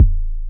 edm-kick-58.wav